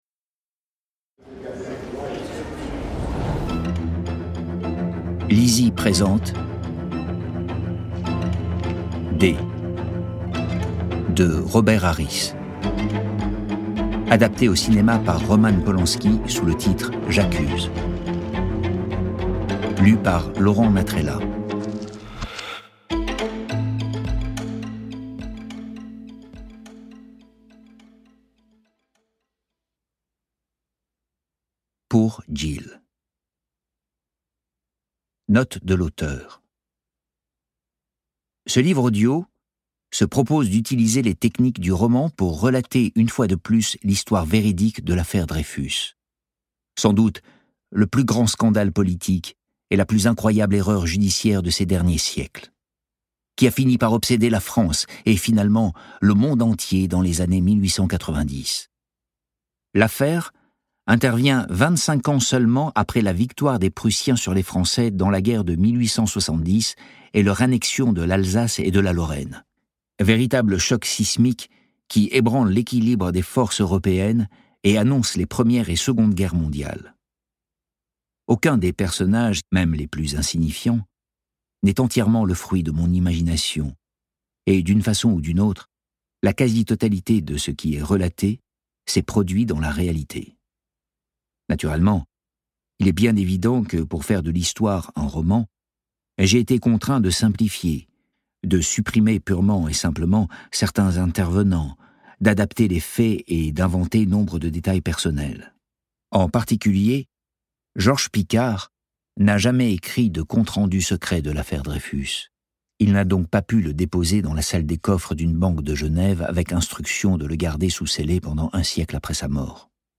je découvre un extrait - J'accuse de Robert HARRIS